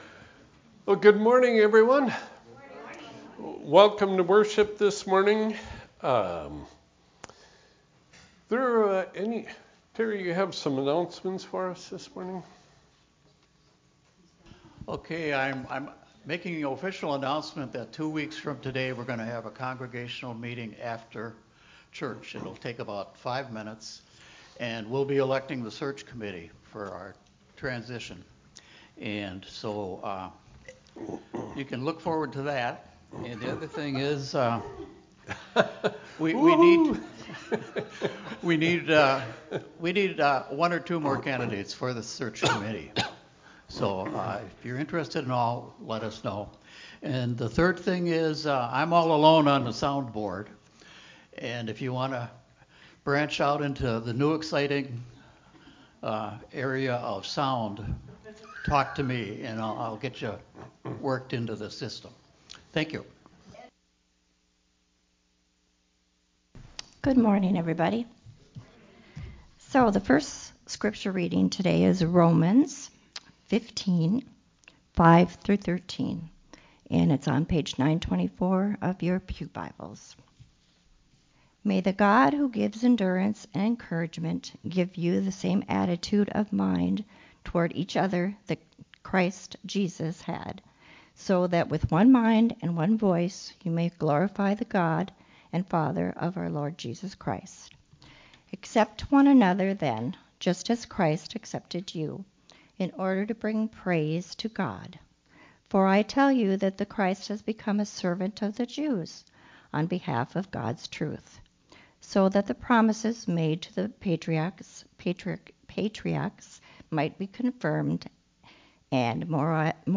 sermon.mp3